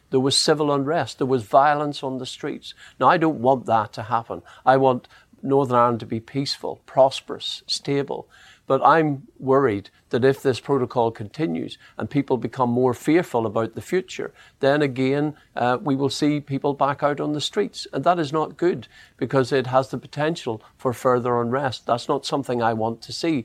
Speaking today to the BBC, Mr Donaldson says there could also be a return of some of the violence seen earlier this year over the protocol: